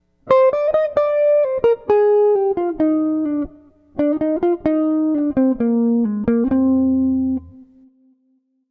Escala diatônica menor natural
Escala Diatônica Menor – mais usada com intenção de melancolia, introspecção, lamentação.